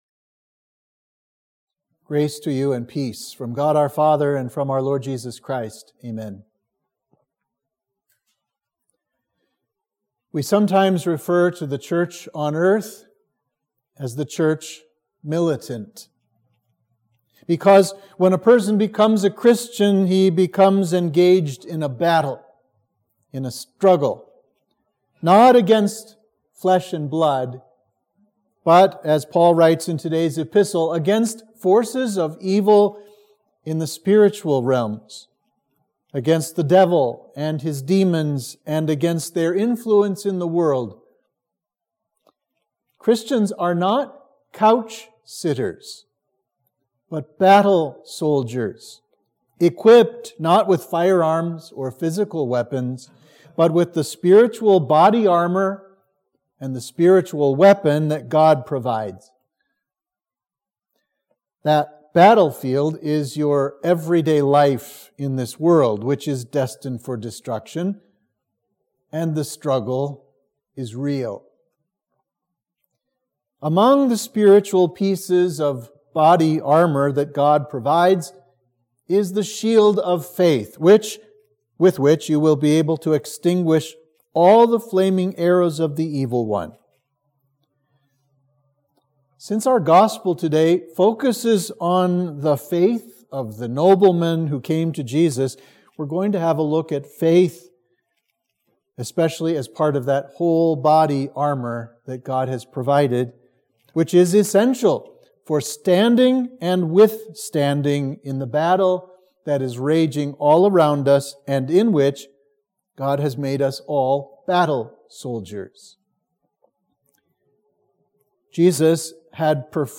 Sermon for Trinity 21